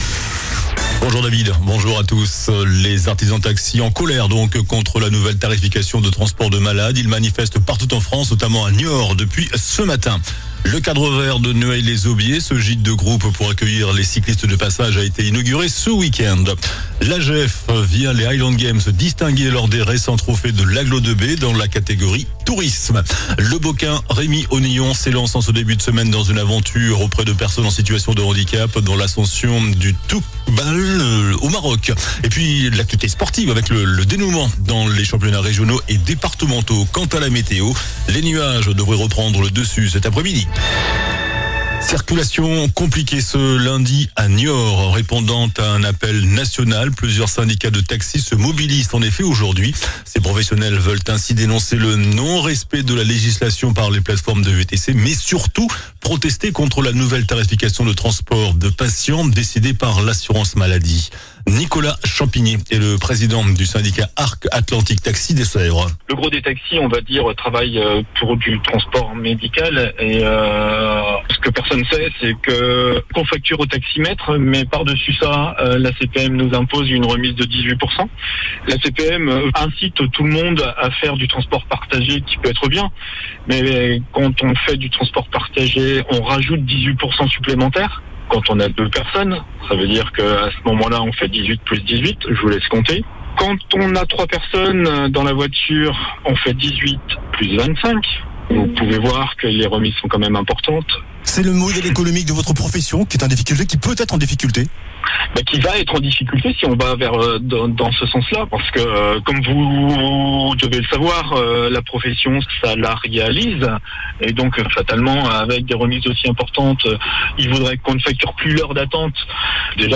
JOURNAL DU LUNDI 19 MAI ( MIDI )